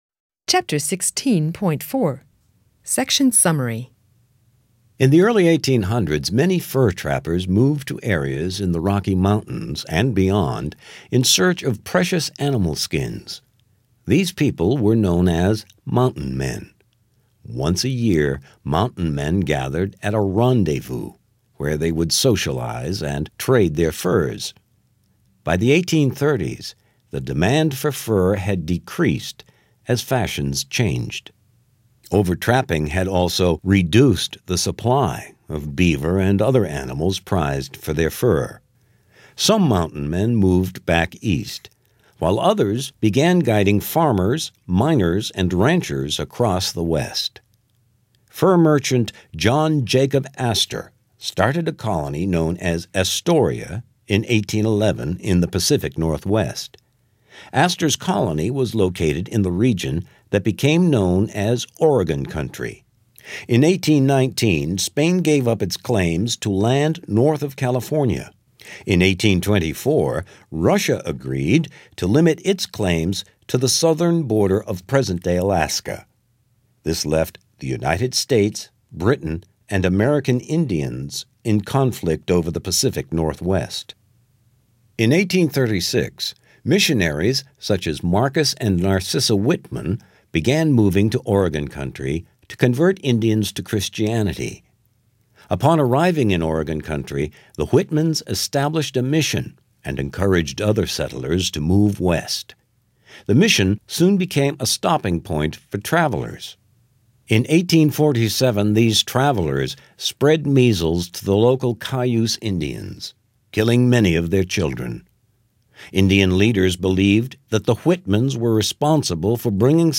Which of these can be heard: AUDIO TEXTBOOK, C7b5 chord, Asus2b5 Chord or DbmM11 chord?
AUDIO TEXTBOOK